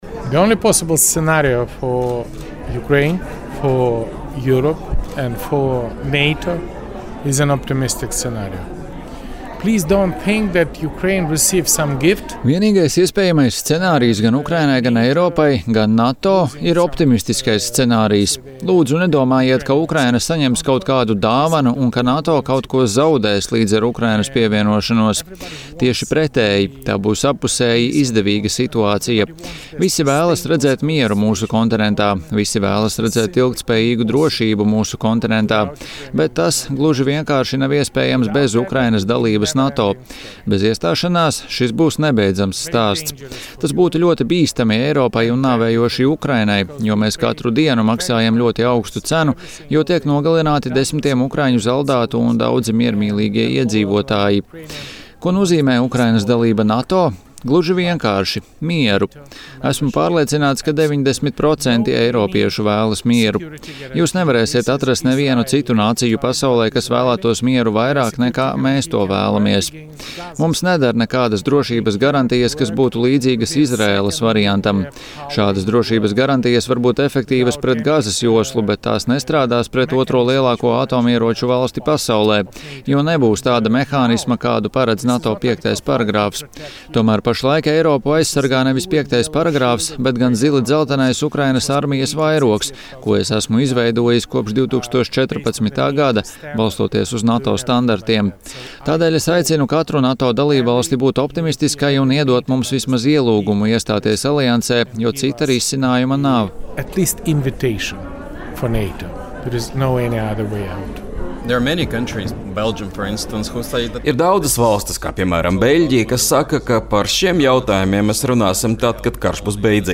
Ukrainai jau pārāk bieži ir dzirdējusi apgalvojumus, ka "NATO durvis ir atvērtas", tādēļ no šonedēļ Viļņā notiekošā NATO dalībvalstu līderu samita sagaida skaidri formulētu ielūgumu, kas būtu pirmais solis ceļā uz Ukrainas pievienošanos aliansei. Šādu viedokli ekskluzīvā intervijā Latvijas Radio pauda iepriekšējais Ukrainas prezidents Petro Porošenko.